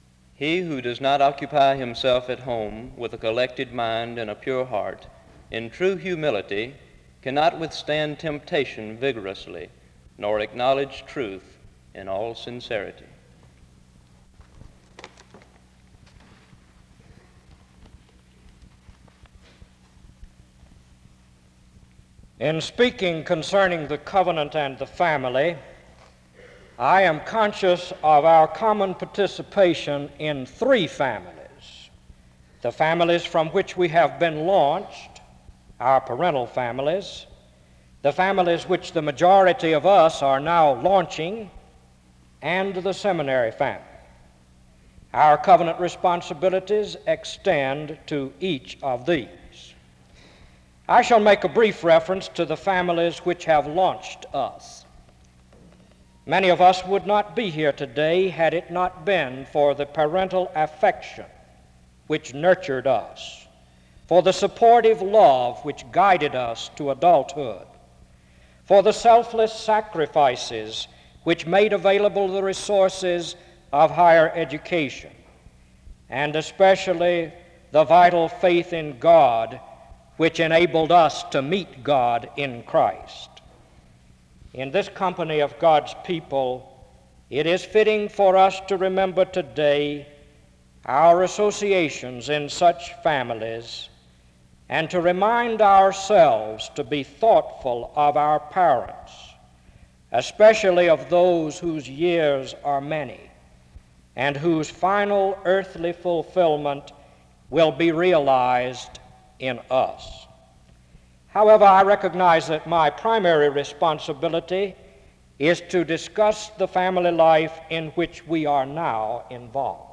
SEBTS Chapel
• Wake Forest (N.C.)